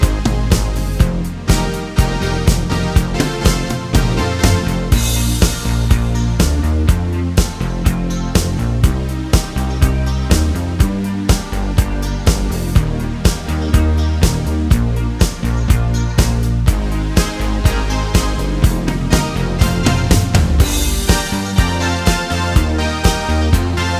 no Backing Vocals Soundtracks 3:58 Buy £1.50